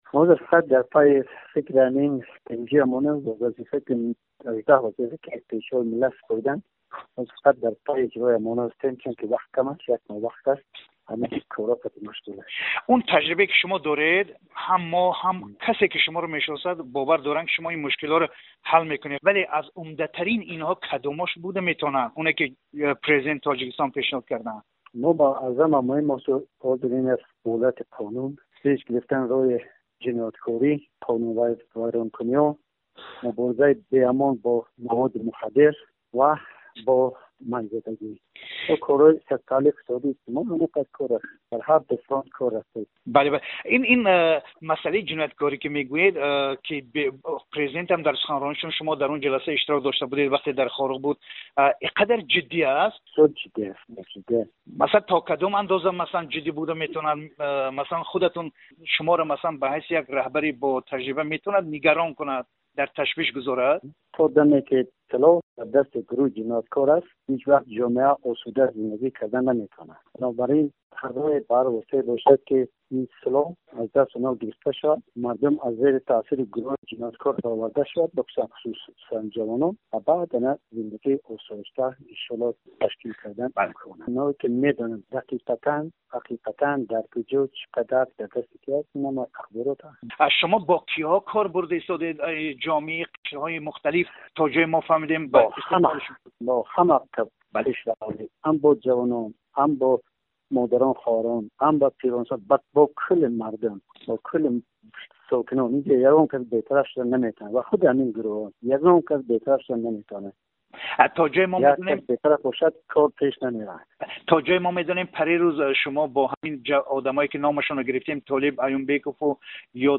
Гуфтугӯ бо Ёдгор Файзов, раиси тозатаъйини Бадахшон